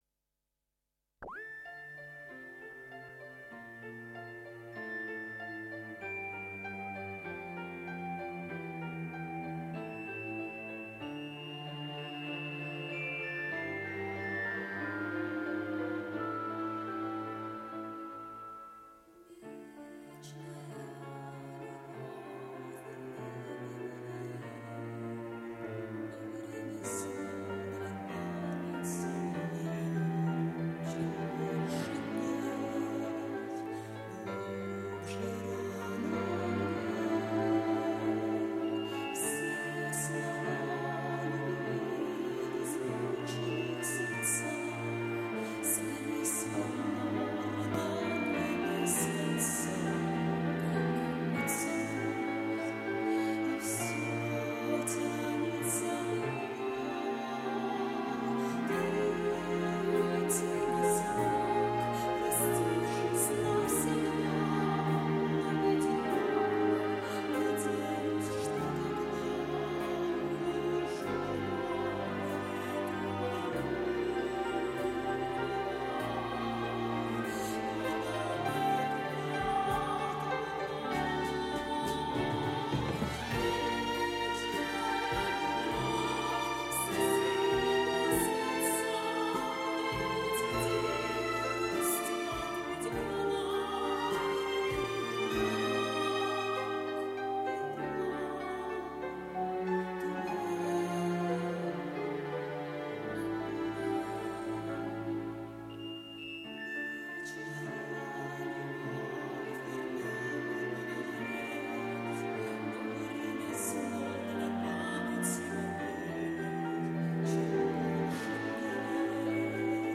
Запись 1991 год Стерео Дубль.